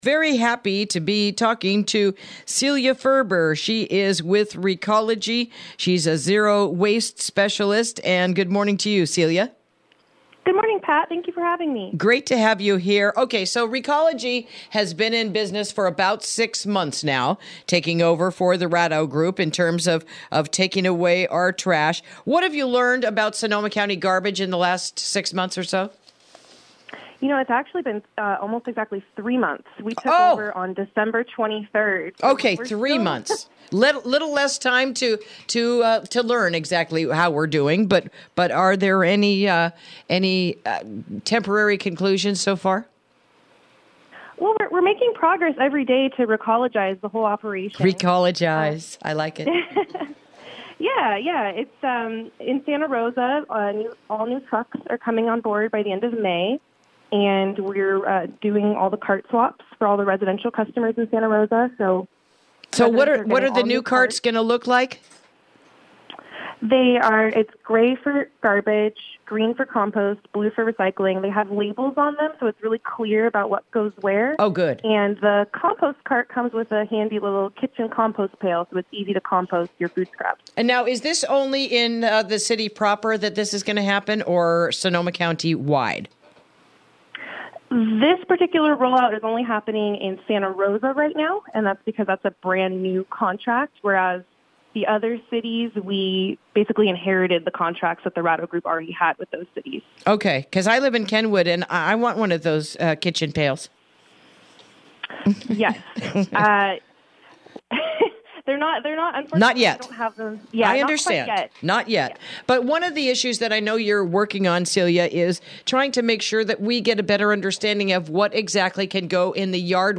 Interview: Recology Composting Beginning a New Era of Recycling in the North Bay